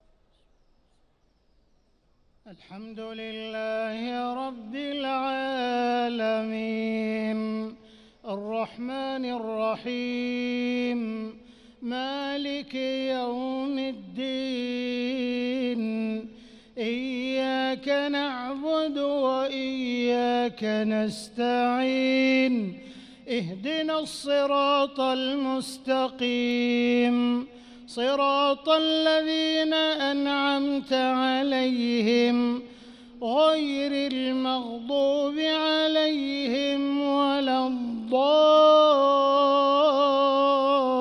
صلاة المغرب للقارئ عبدالرحمن السديس 24 رمضان 1445 هـ
تِلَاوَات الْحَرَمَيْن .